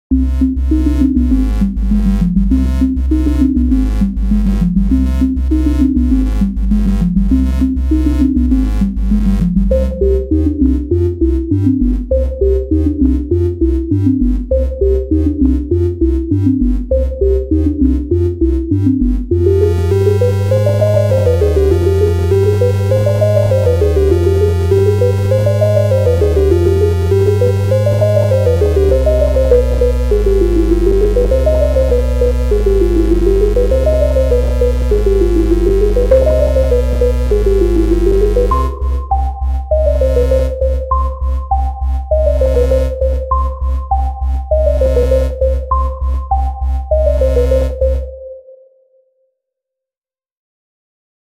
BPM200